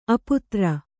♪ aputra